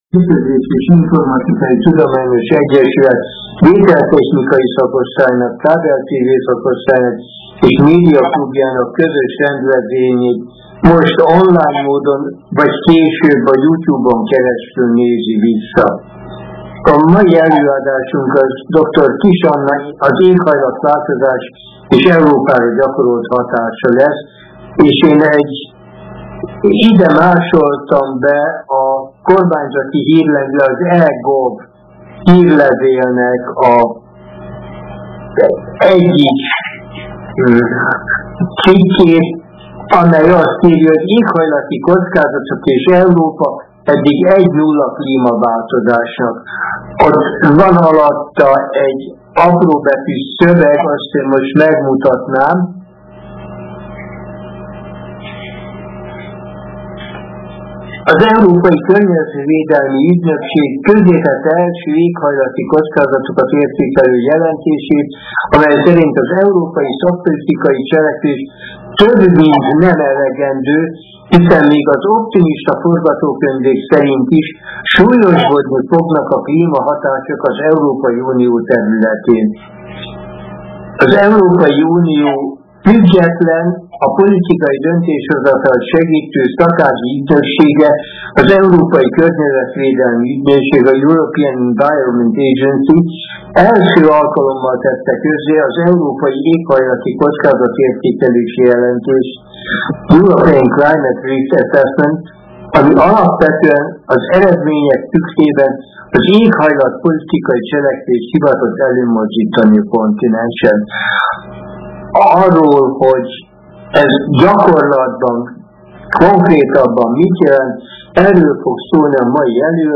Az előadás hanganyaga elérhető ITT , képanyaga elérhető ITT.